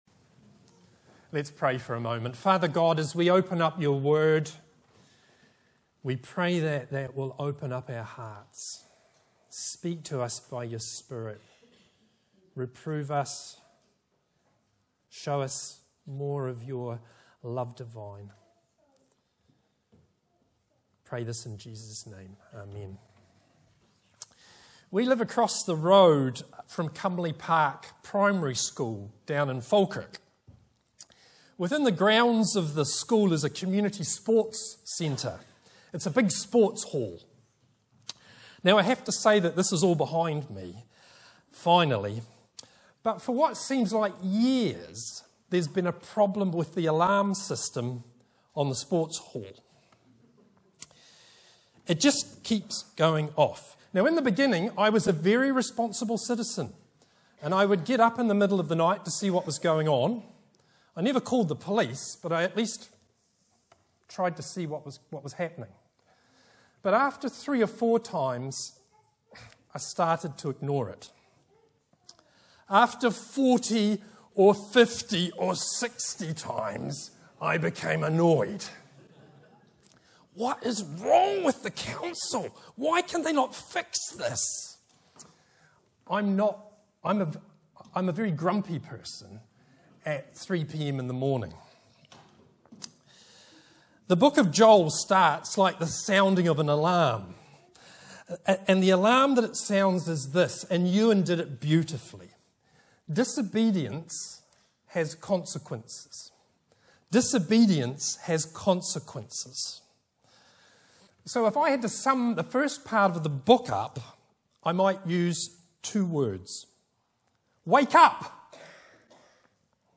Bible references: Joel 1:1-20 Location: Brightons Parish Church Show sermon text Sermon keypoints: - wake-up!